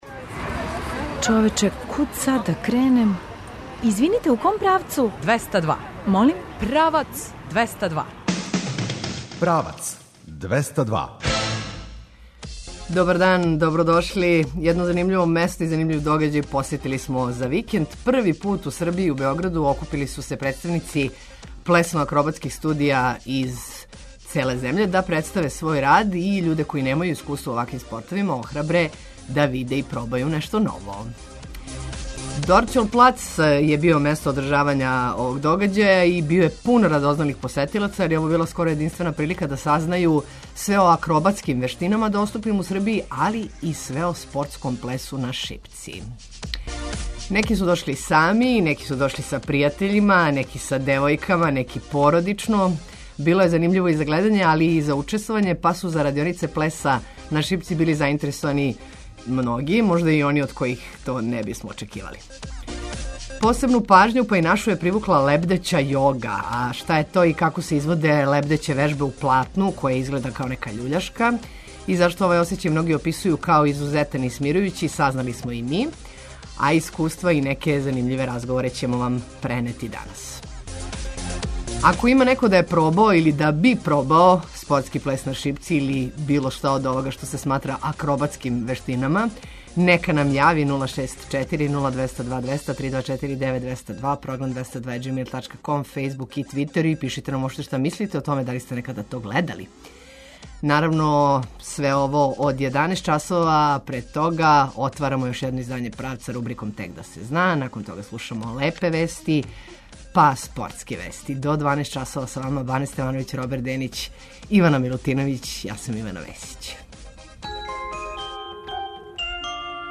Како се изводе лебдеће вежбе у платну које изгледа као љуљашка и зашто овај осећај многи описују као изузетан и смирујући сазнали смо и ми а искуства и неке занимљиве разговоре вам преносимо у Правцу 202.